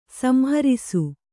♪ samharisu